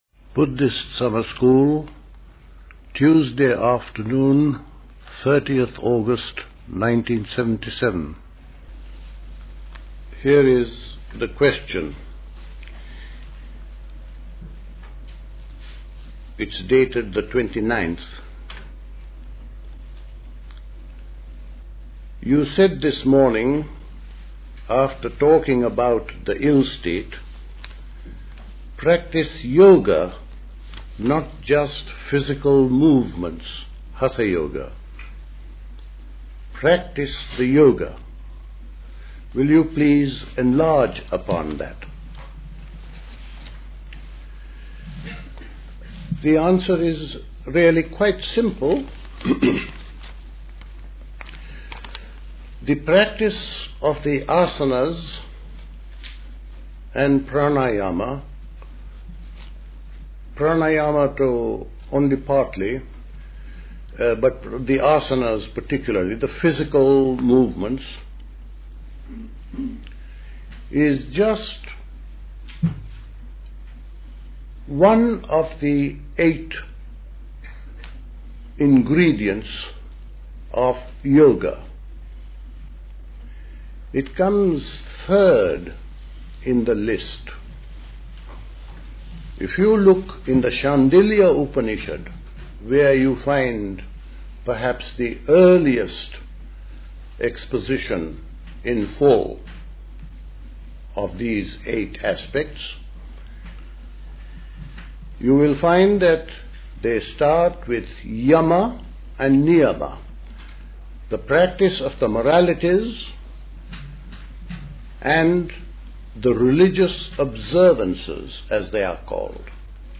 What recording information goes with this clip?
at High Leigh Conference Centre, Hoddesdon, Hertfordshire The Buddhist Society Summer School Talks